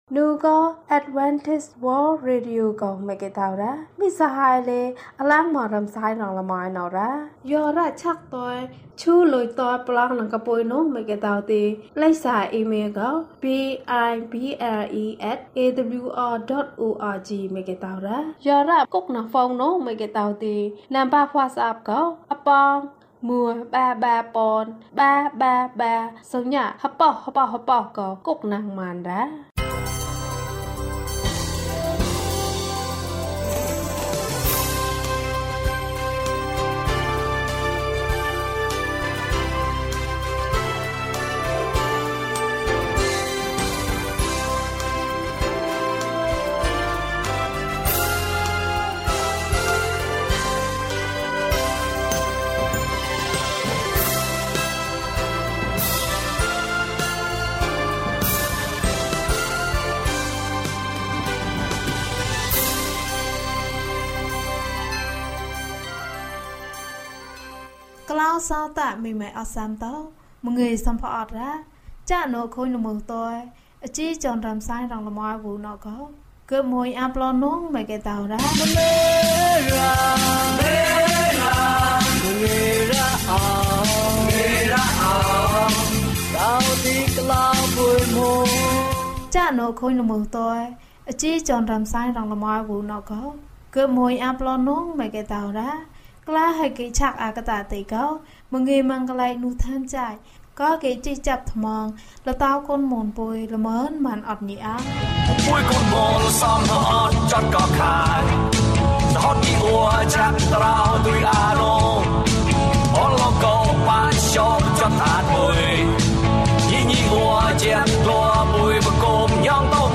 အပြစ်အတွက် ယေရှုအသေခံတယ်။ ကျန်းမာခြင်းအကြောင်းအရာ။ ဓမ္မသီချင်း။ တရားဒေသနာ။